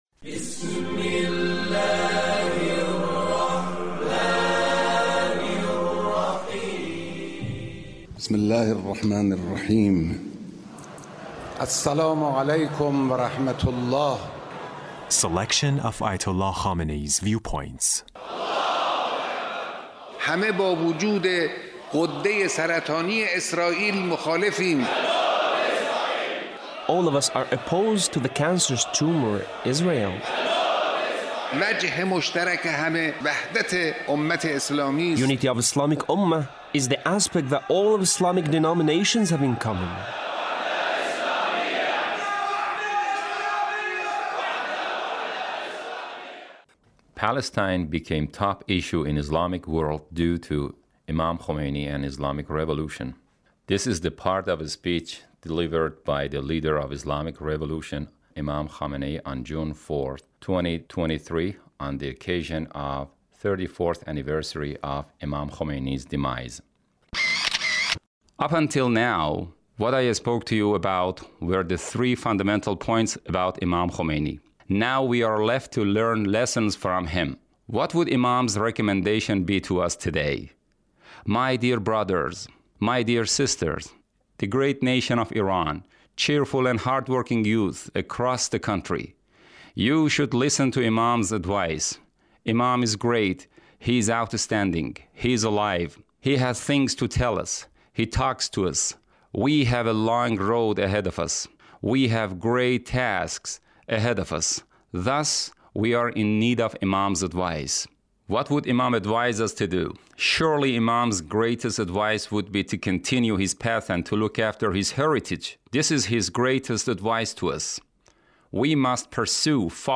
Leader's Speech on the occasion of the 34th ََAnniversary of Imam Khomeini’s Demise. 2023